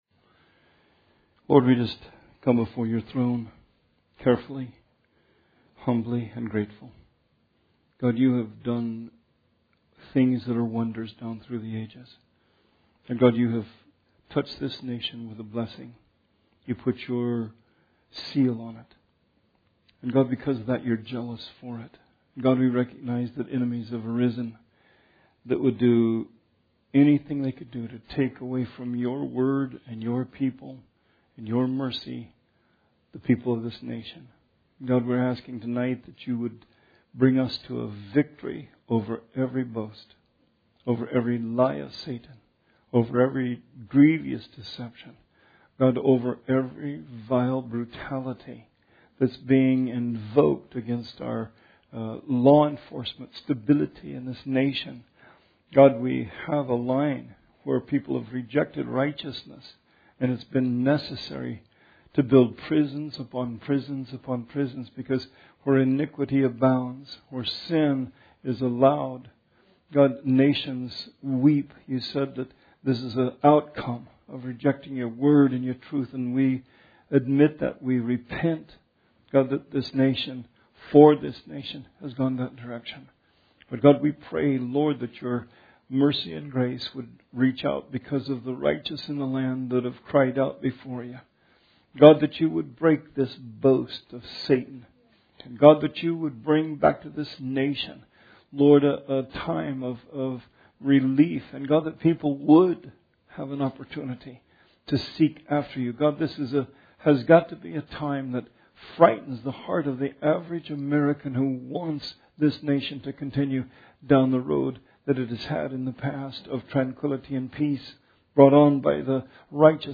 Bible Study 8/26/20